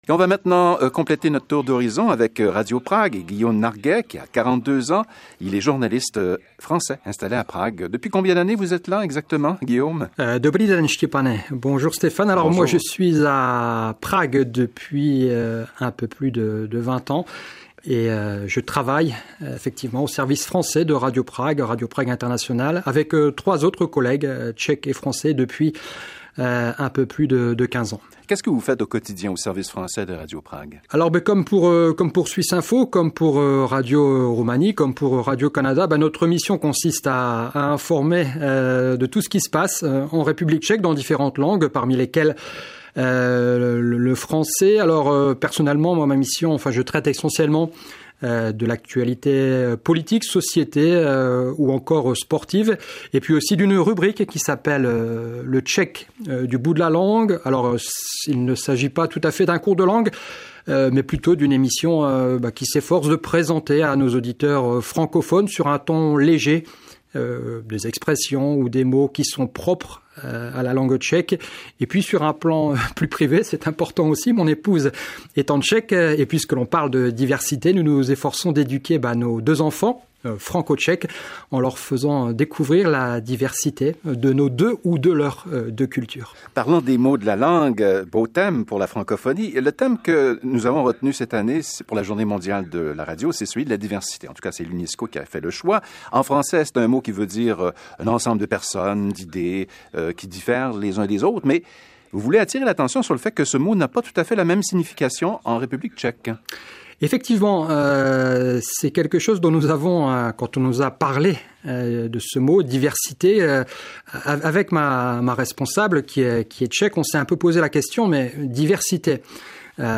C’est ainsi que pour la première fois, dans cette émission de 20 minutes, Radio Canada International réunit trois partenaires internationaux pour vous faire découvrir leurs diversités.